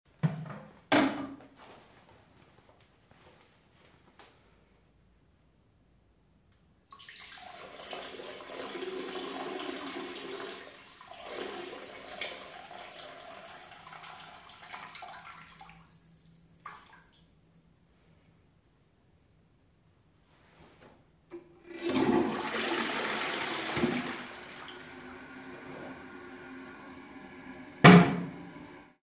• Качество: высокое
Шум струи мочи в унитаз, смытый водой после завершения дела